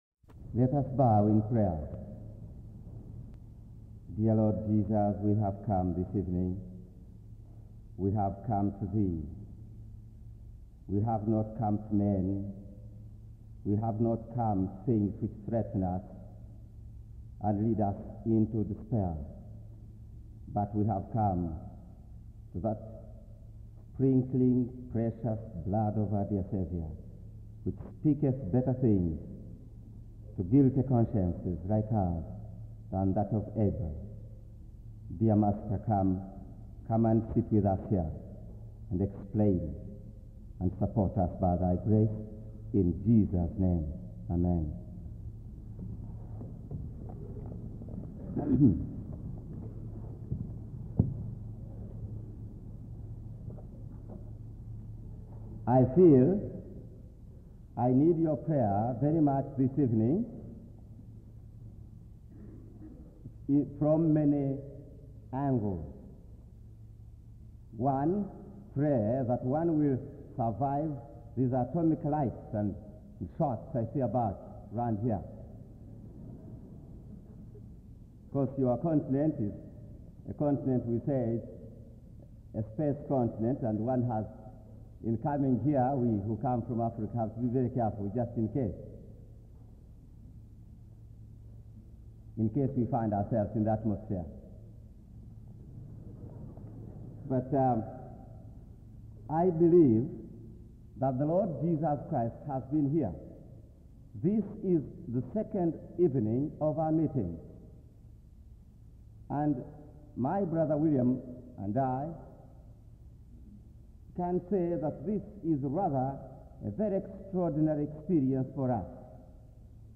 In this sermon, the preacher emphasizes the importance of being practical and simple in our faith, as that is what God desires. He uses the story of Jacob to illustrate this point, highlighting how Jacob struggled and worked hard for 20 years before receiving God's blessings.